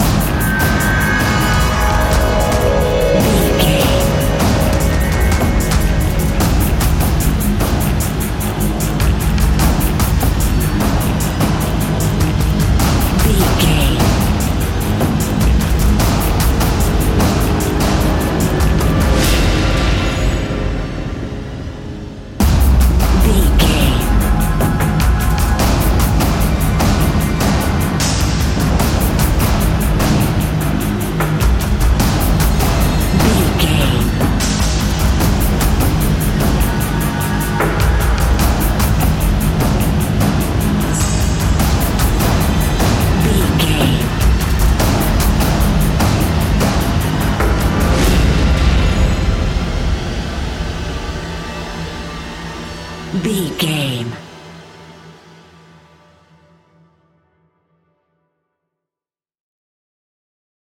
Fast paced
In-crescendo
Ionian/Major
industrial
dark ambient
synths